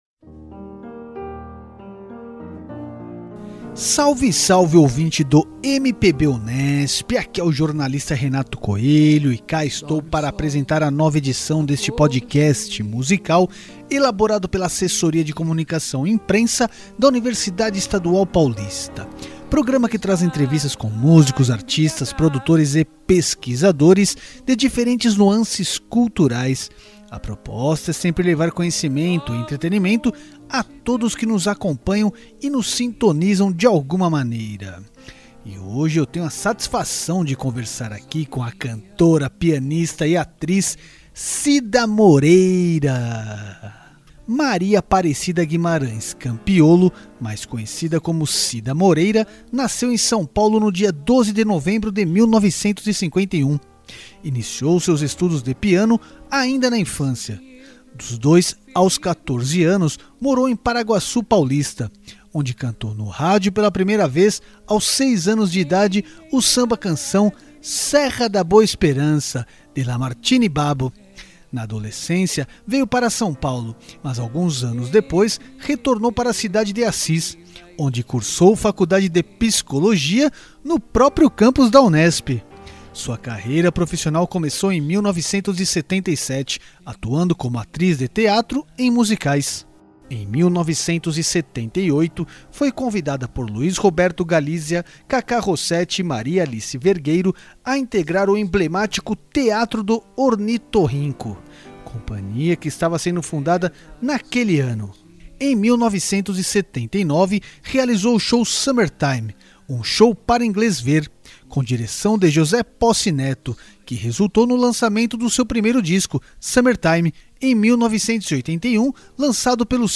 Cida Moreira é a entrevistada destaque desta edição do MPB Unesp.
O PodMPB traz áudios de entrevistas com pesquisadores e músicos de diferentes gêneros, com a proposta de oferecer entretenimento e conhecimento ao ouvinte.